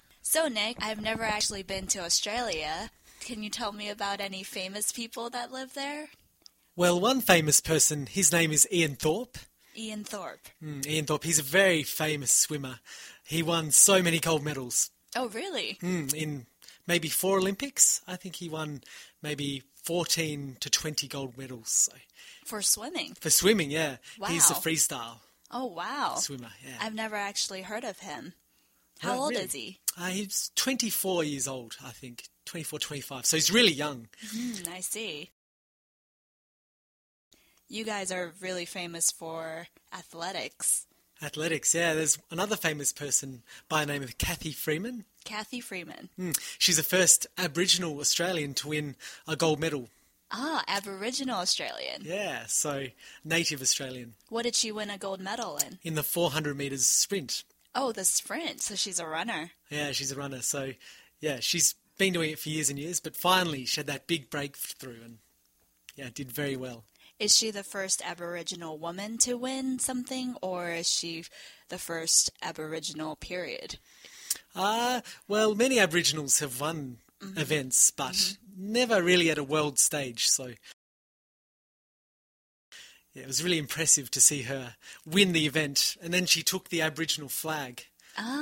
在线英语听力室英文原版对话1000个:1061 Famous Australians的听力文件下载,原版英语对话1000个,英语对话,美音英语对话-在线英语听力室